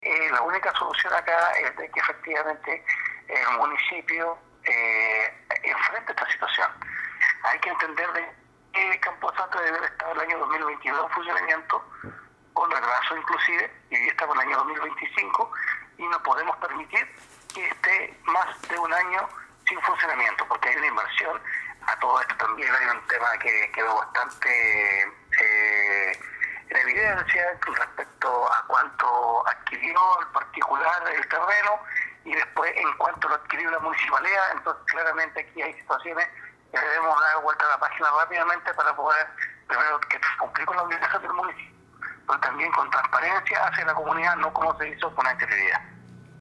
Andrés Ibáñez planteó que la nueva administración debe realizar todos los procedimientos de forma rápida y de forma transparente para poder contar en el plazo de un año, el nuevo campo santo de la comuna en funcionamiento.